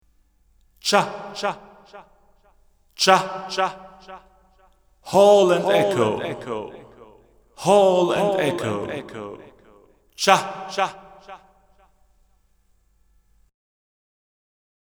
Tältä kaiut ja viiveet kuulostavat (muutin viiveajat Tap Tempo -toiminnalla):
Hall + Echo
hall-echo.mp3